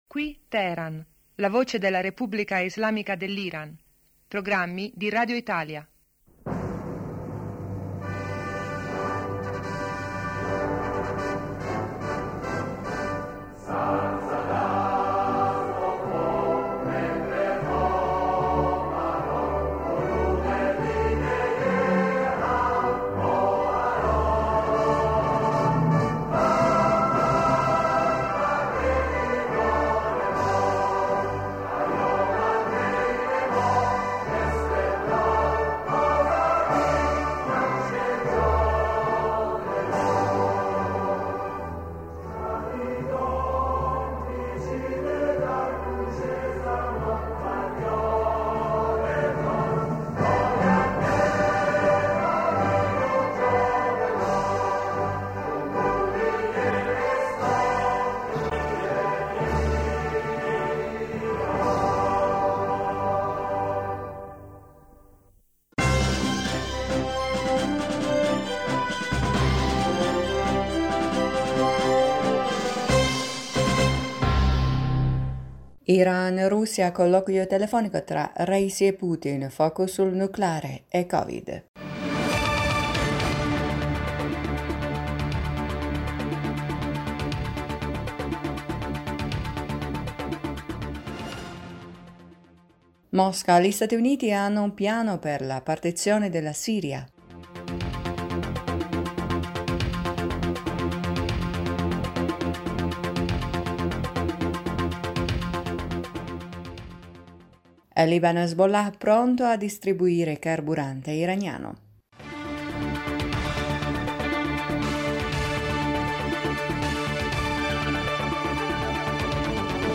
Ecco i titoli più importanti del nostro radiogiornale:1-Iran-Russia: colloquio telefonico tra Raisi e Putin.